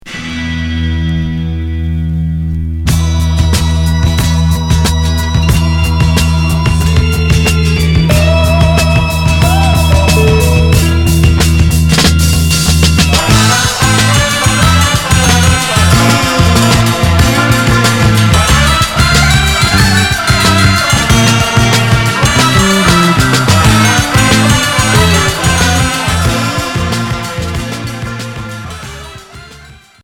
Groove progressif Unique 45t